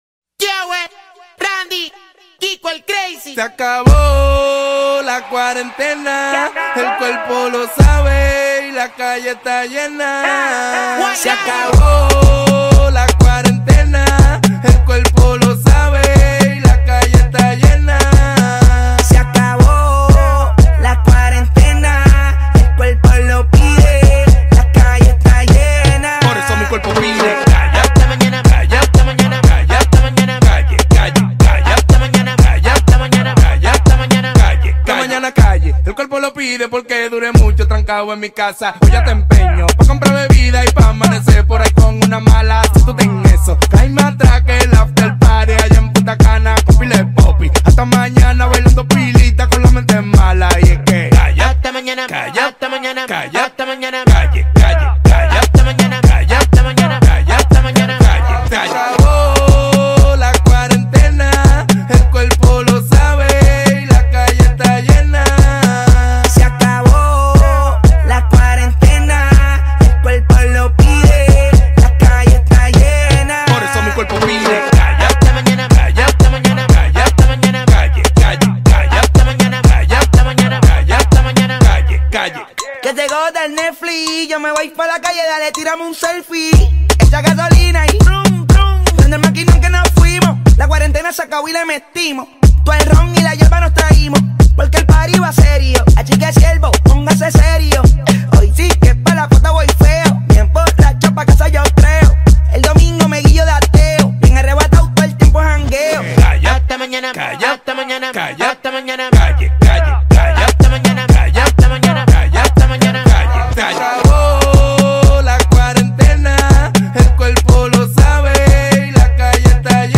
реггетон-композиция